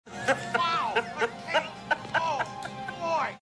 PLAY risa malos de robocop 2
risa-malos-de-robocop.mp3